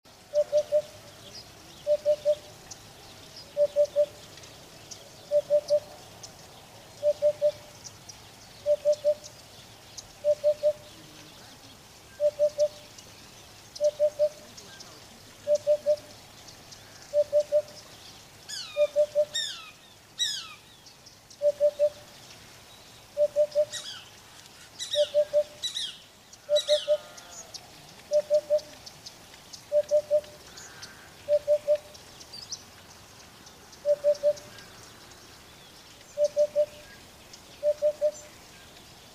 Голос удода - свист, мяуканье, щебетание
• Категория: Удод
• Качество: Высокое